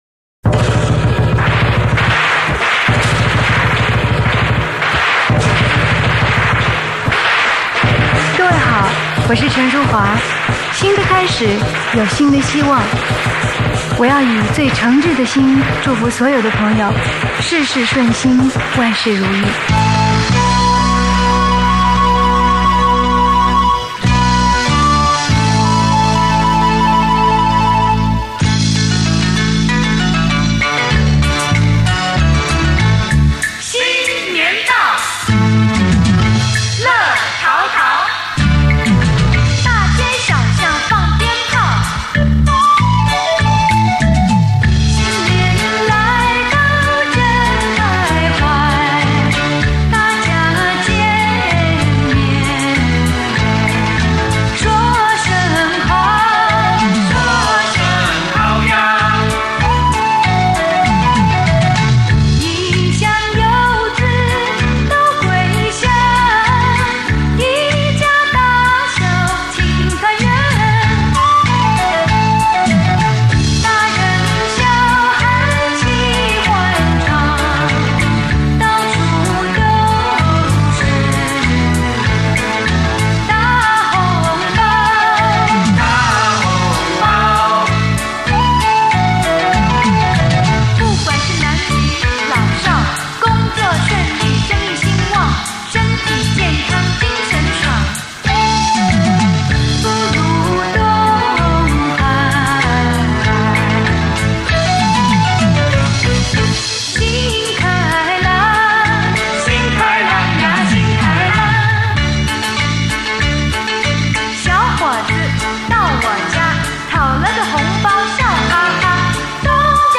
生于台湾的流行歌坛知名女歌手。